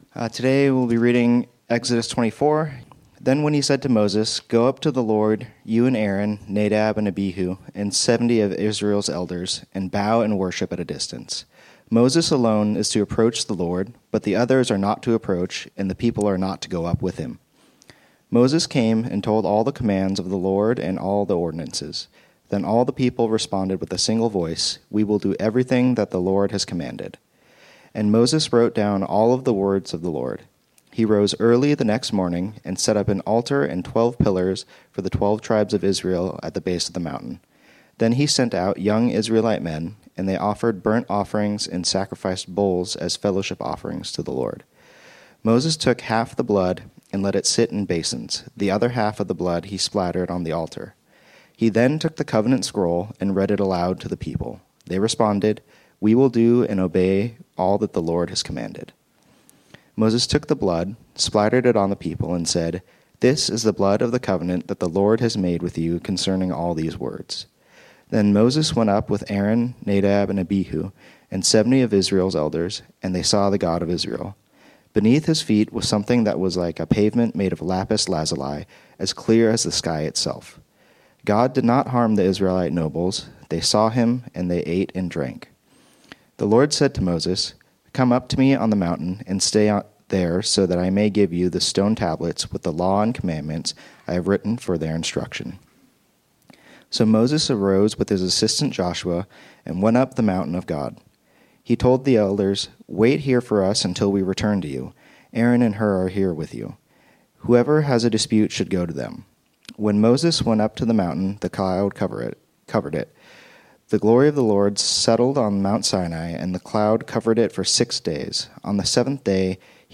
This sermon was originally preached on Sunday, April 6, 2025.